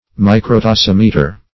Search Result for " microtasimeter" : The Collaborative International Dictionary of English v.0.48: Microtasimeter \Mi`cro*ta*sim"e*ter\, n. [Micro- + tasimeter.]
microtasimeter.mp3